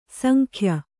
♪ sankhya